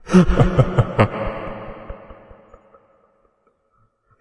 笑声 " 短暂的邪恶笑声3
描述：一个男人的声音很快笑，对恐怖的气氛很有用
标签： 恐惧 悬疑 恐怖 笑声 令人毛骨悚然 闹鬼 戏剧 邪恶 氛围 阴险 恐怖 怪异 可怕的 可怕的 幻影
声道立体声